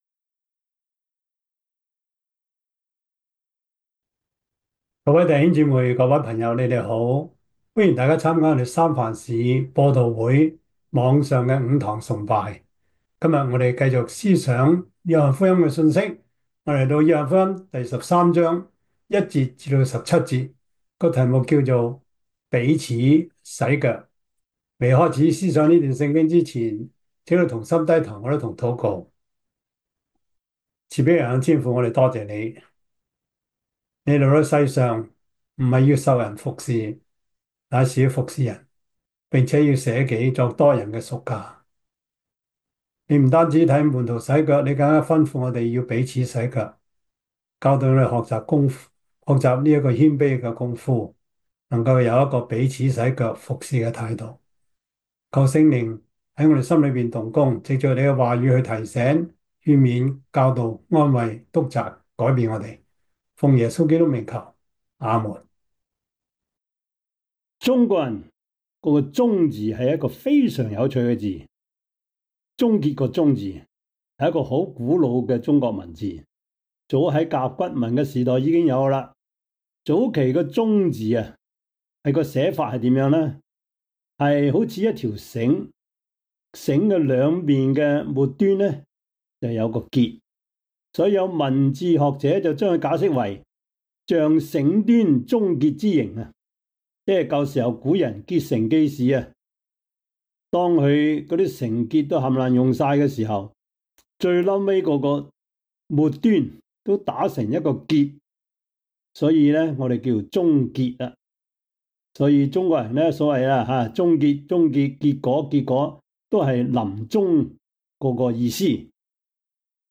約翰福音 13:1-17 Service Type: 主日崇拜 約翰福音 13:1-17 Chinese Union Version